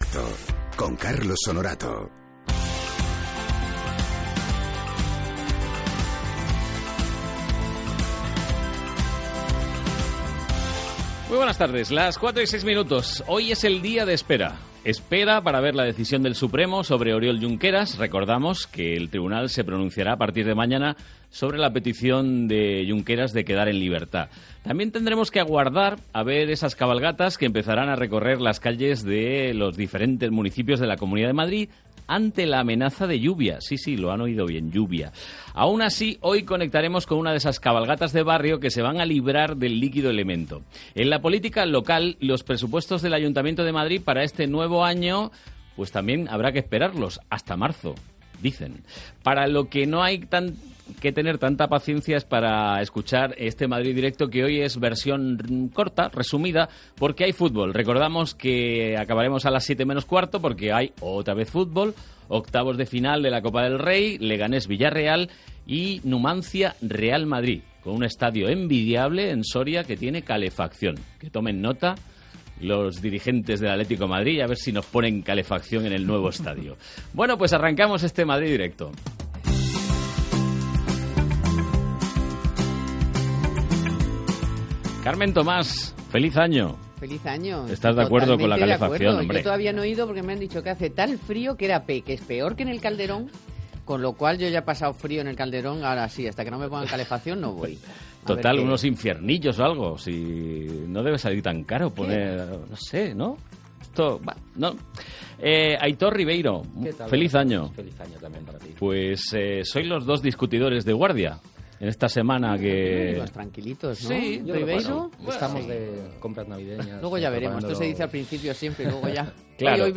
Tertulia
Nos visita el grupo musical Tenesse.